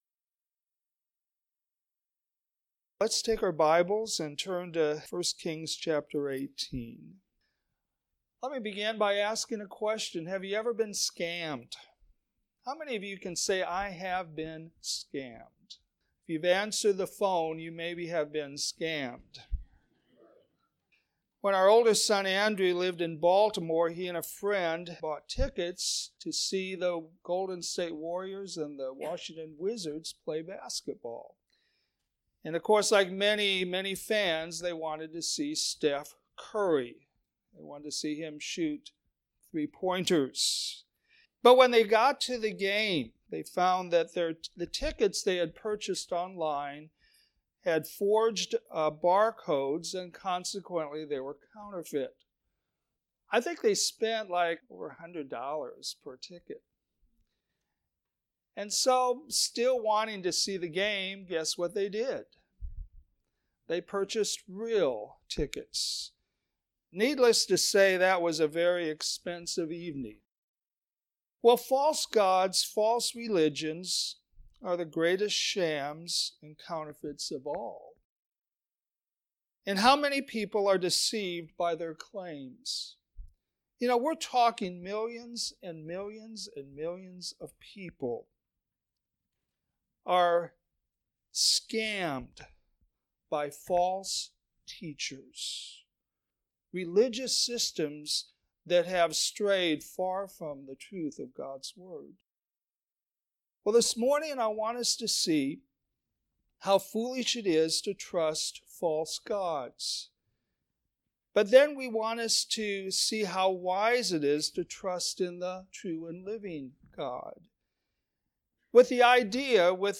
Sermons
Sunday AM